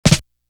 Flam Roll.wav